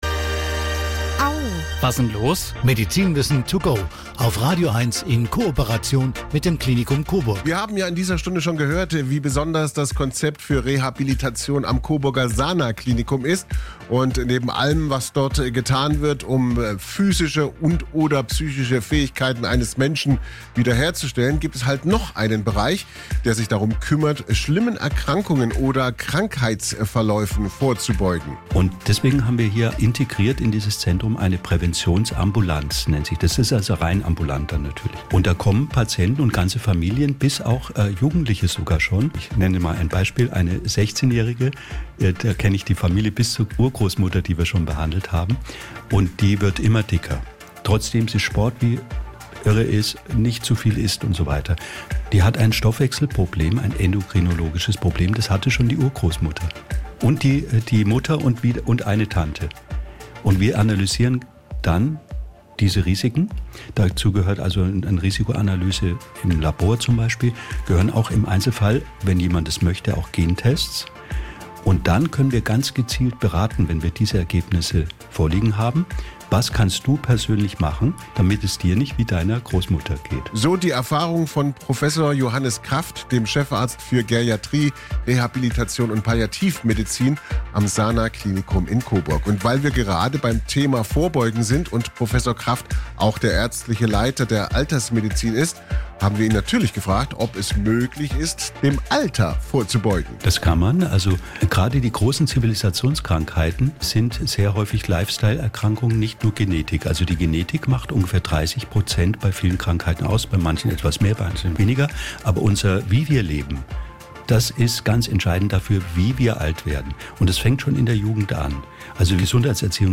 Unser Lokalsender Radio Eins im Experteninterview: